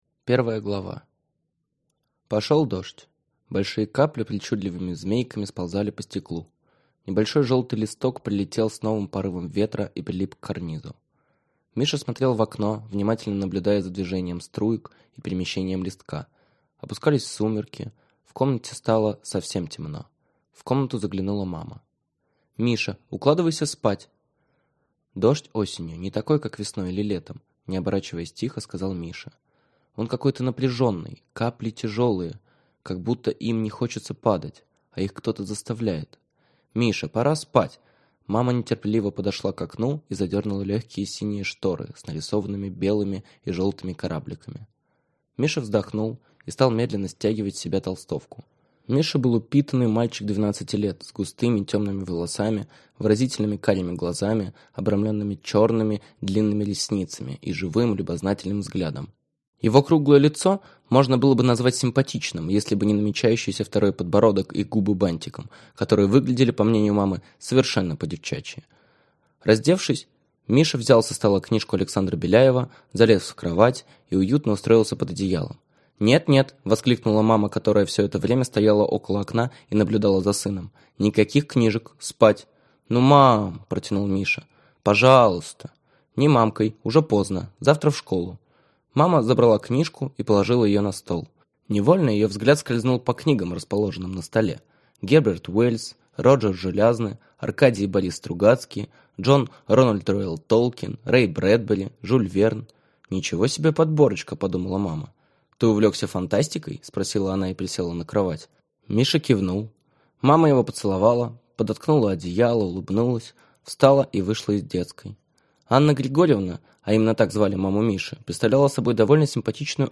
Аудиокнига КЛАССНАЯ | Библиотека аудиокниг